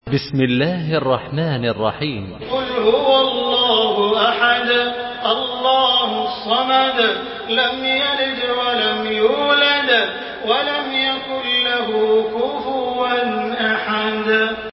تحميل سورة الإخلاص بصوت تراويح الحرم المكي 1428
مرتل